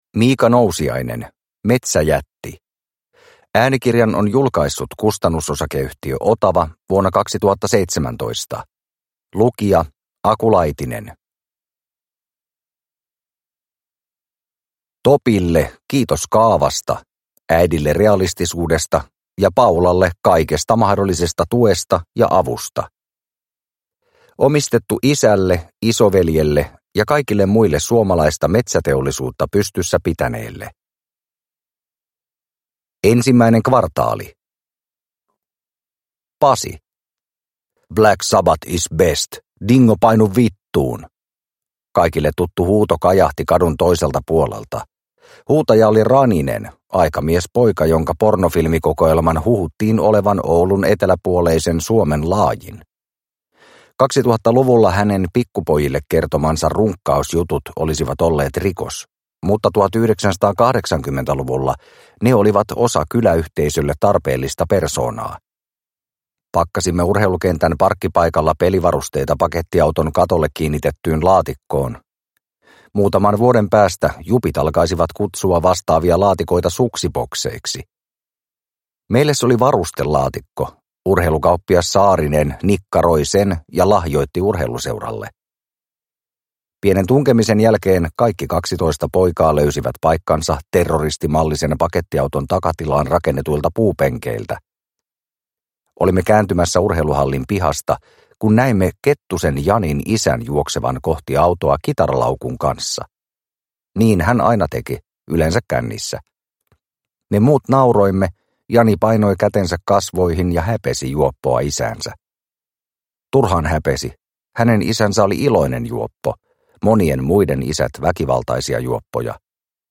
Metsäjätti – Ljudbok – Laddas ner